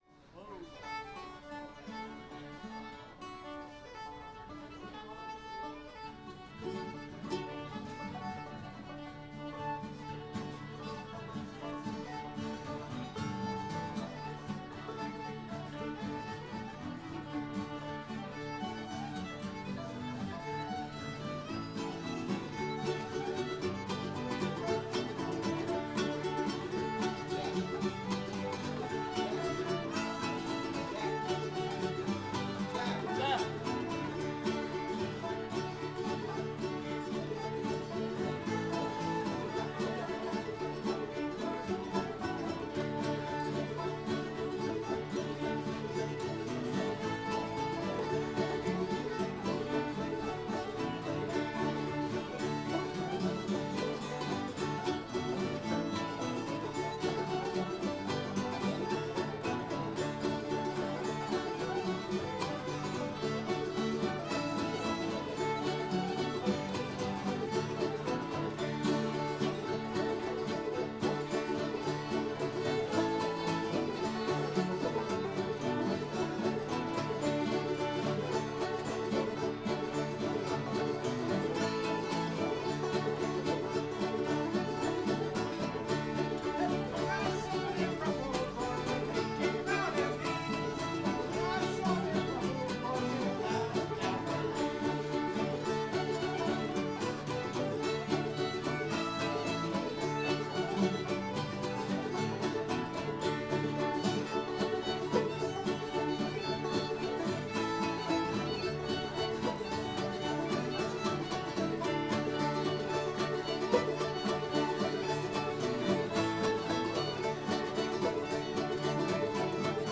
ruffled drawers [D]